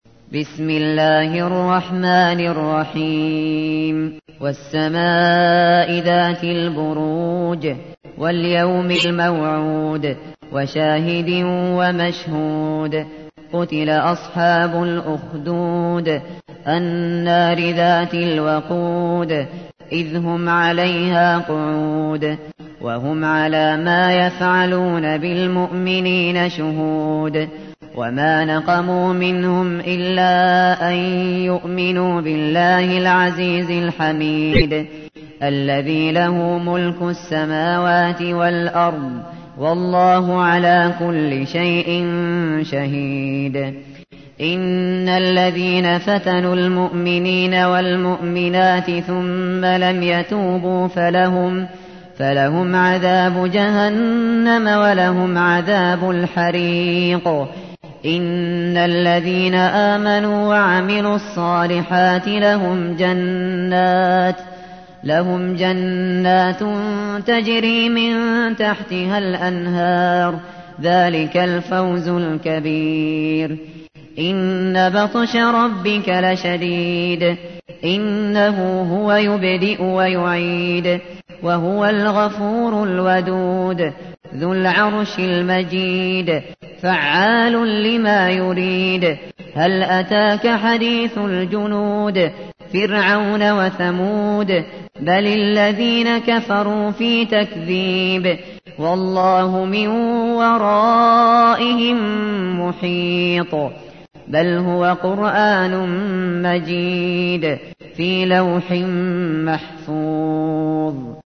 تحميل : 85. سورة البروج / القارئ الشاطري / القرآن الكريم / موقع يا حسين